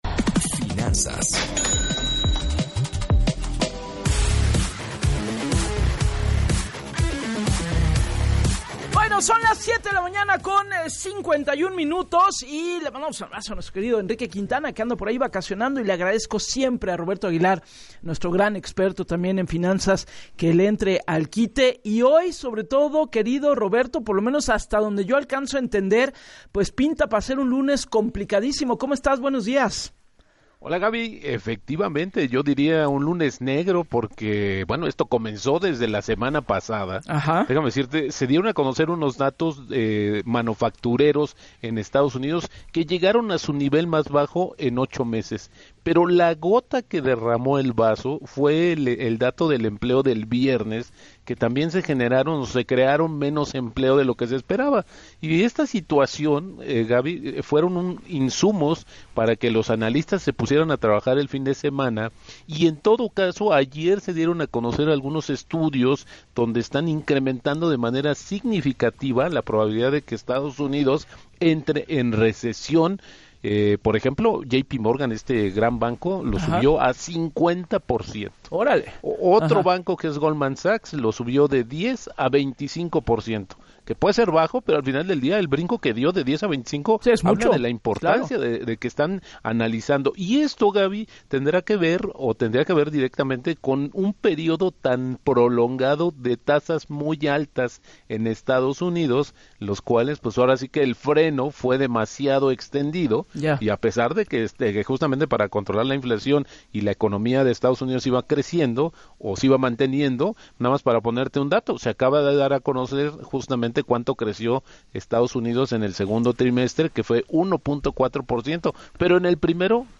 En el espacio de “Así las Cosas” con Gabriela Warkentin, el analista financiero señaló que durante el fin de semana se registraron los datos manufactureros más bajos en ocho meses en el país vecino; el informe de tasa de desempleo de Estados Unidos escaló hasta un 4.3%, lo que derivó en el freno a la inflación que provocó el alza de las tasas en bancos y el desplome bursátil mundial.